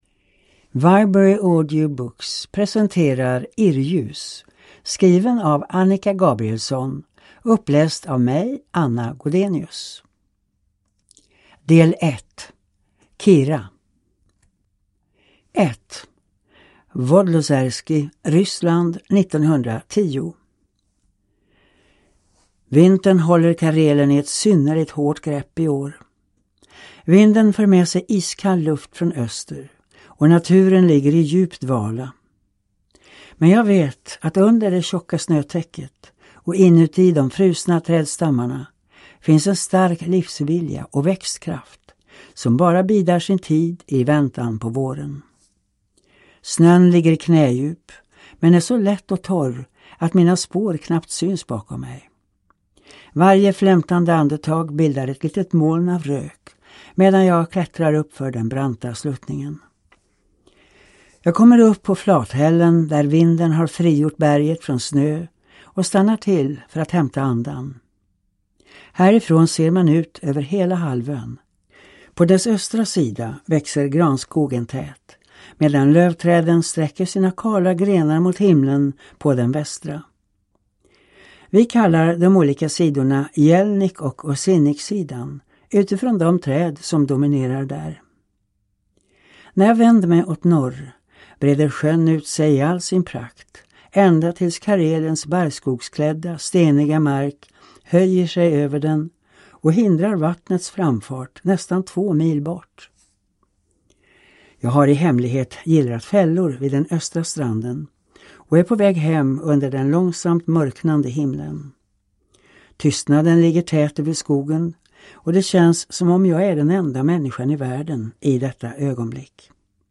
Irrljus (ljudbok) av Annika Gabrielsson